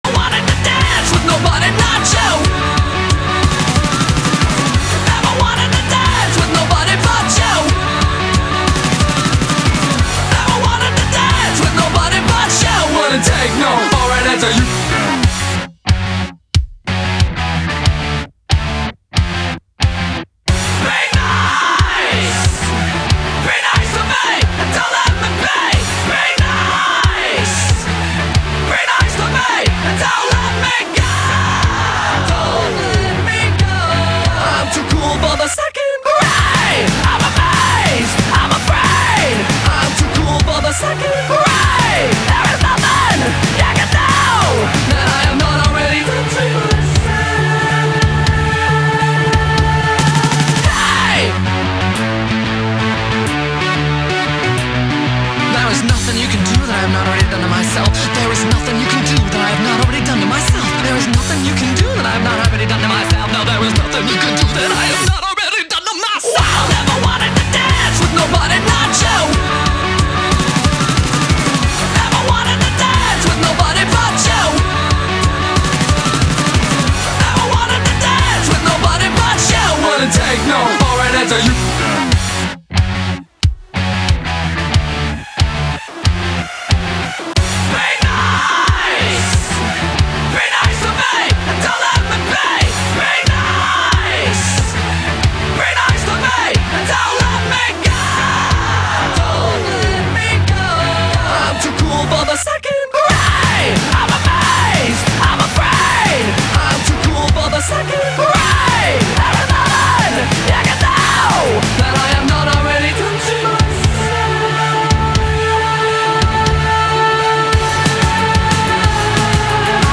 BPM183-183
Audio QualityCut From Video